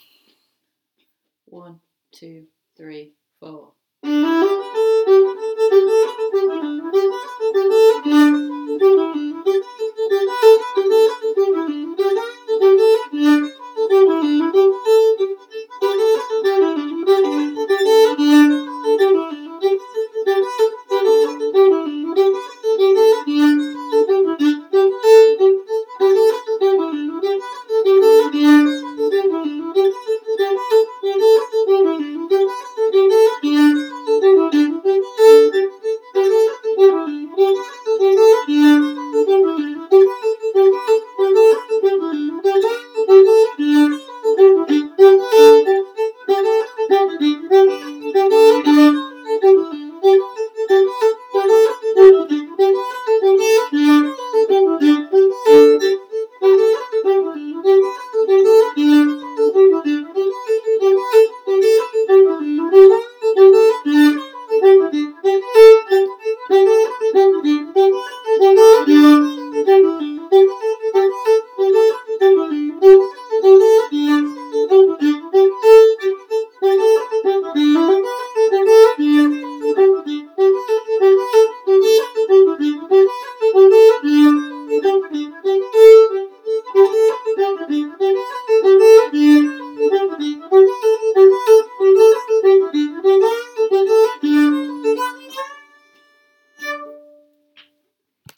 A Part Only